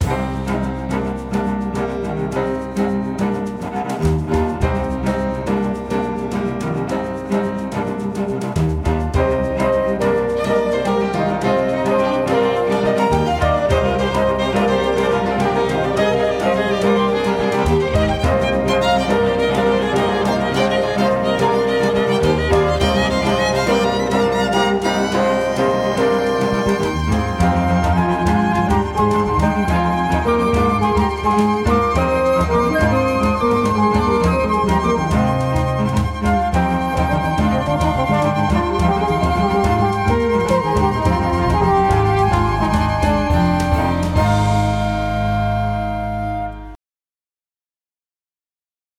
Prizorie: Glavni oder na Dravi
trobenta
flavta
rog
violina
viola
violončelo
klavir
bobni